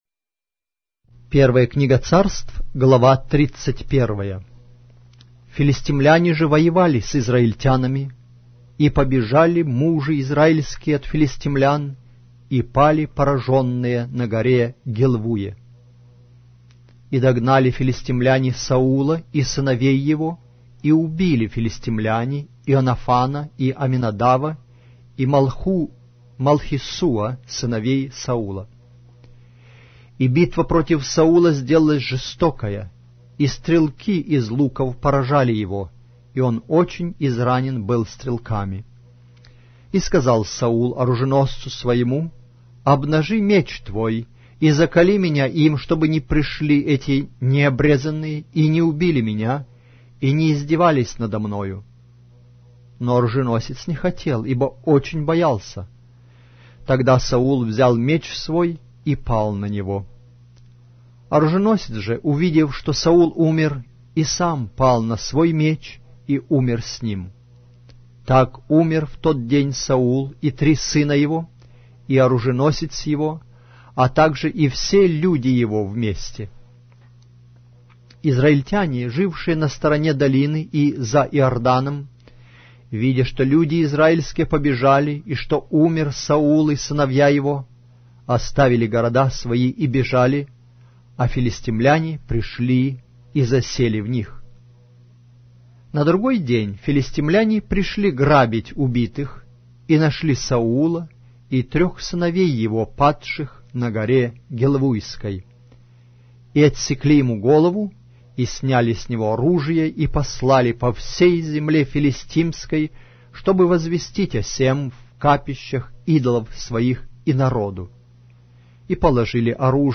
Глава русской Библии с аудио повествования - 1 Samuel, chapter 31 of the Holy Bible in Russian language